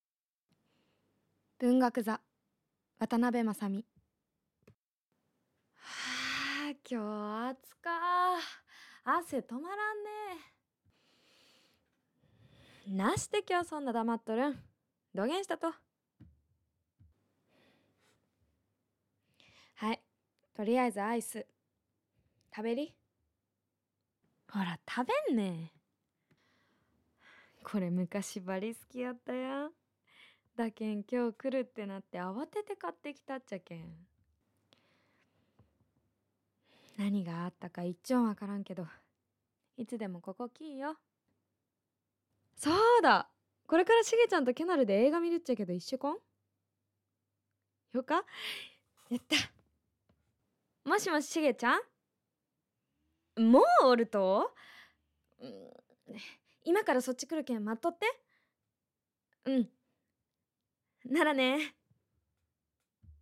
博多弁
ボイスサンプル